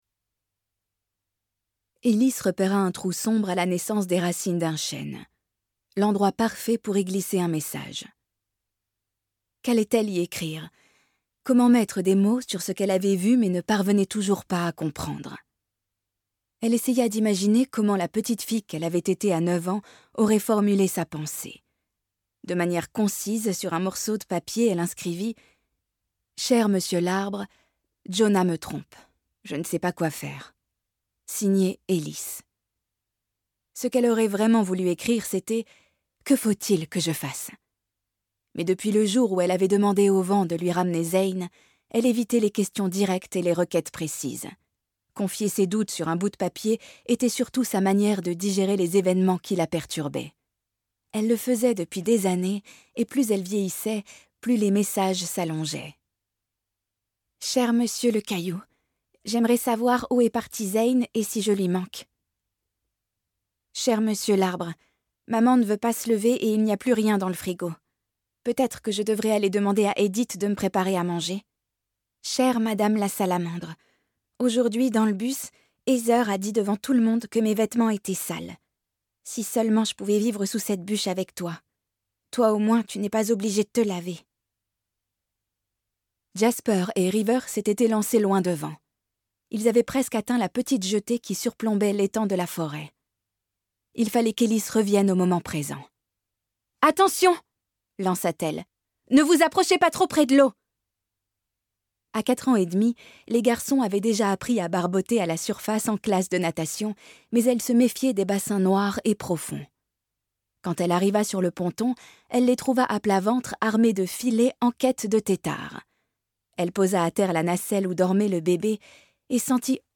Click for an excerpt - Dans la forêt des larmes de Glendy Vanderah